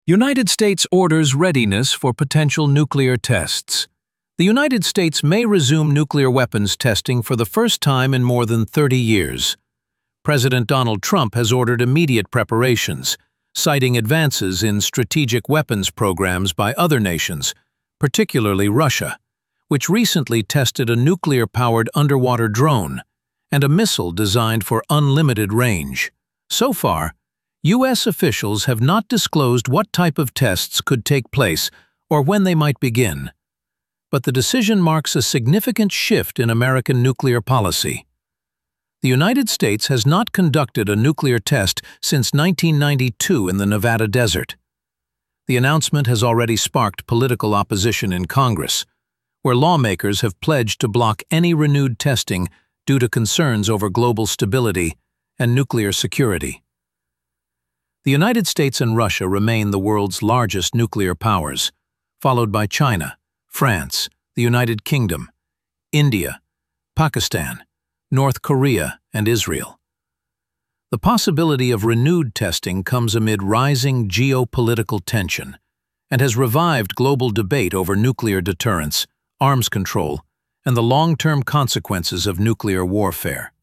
International News Voice · ~45–60 seconds